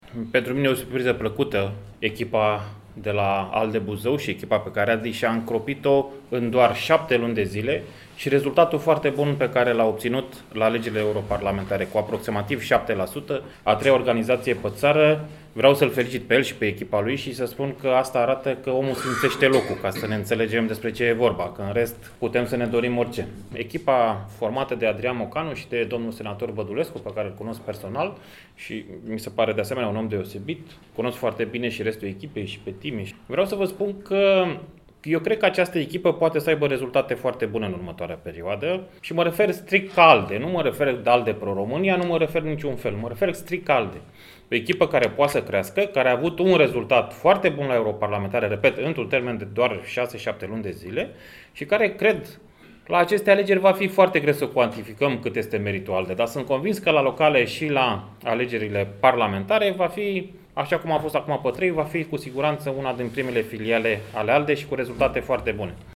În cadrul unei conferințe de presă s-a discutat printre altele și despre rezultatele obținute de către organizația județeană ALDE, care după cum a declarat Toma Petcu, au demonstrat faptul că la Buzău, echipa formată s-a dovedit a fi una închegată, cu potențial pe viitor.